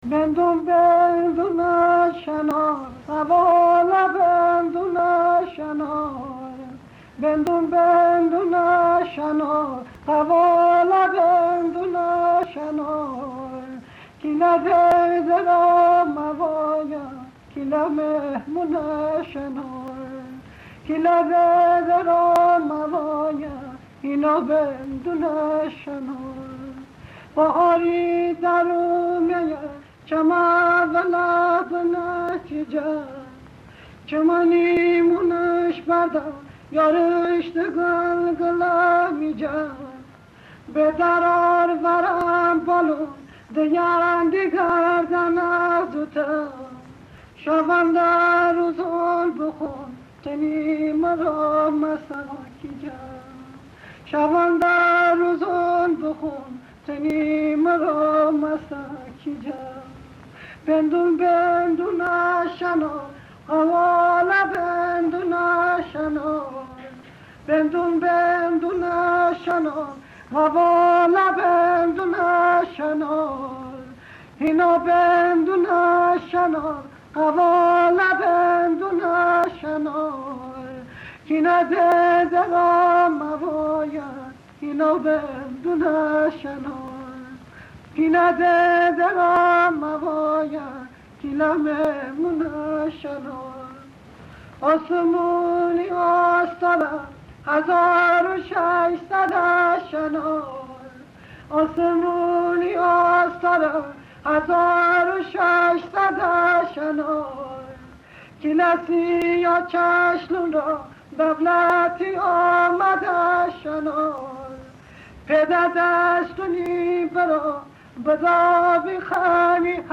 موسیقی زیبای تالشی حنابندان